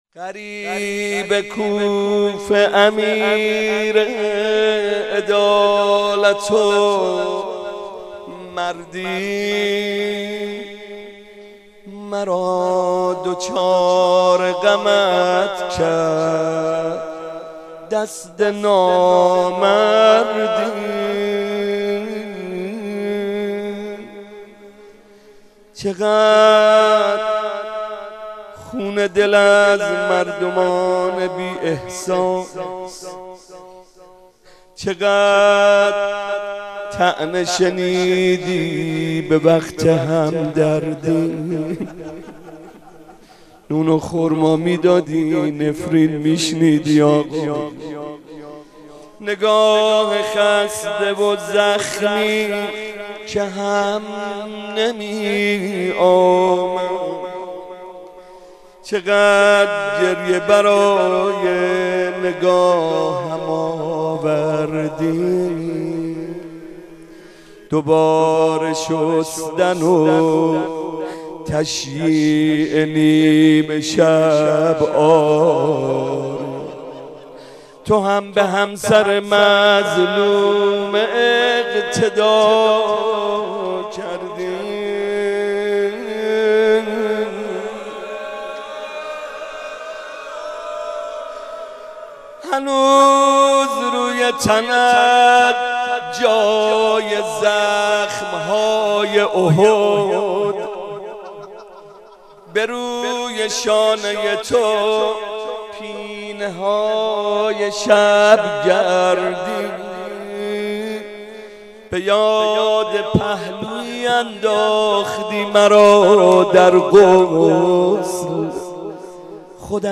مناسبت : شب بیست و دوم رمضان
قالب : روضه